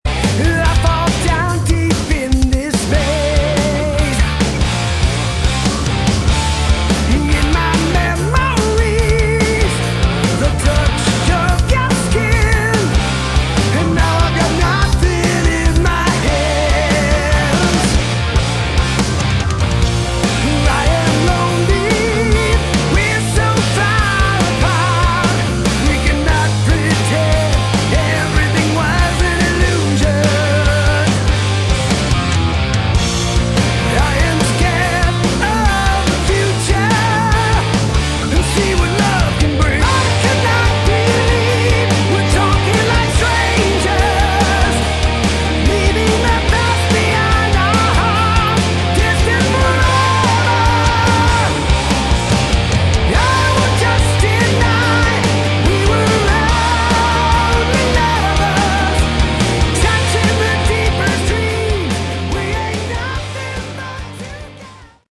Category: Modern Hard Rock
lead vocals, drums
guitars
bass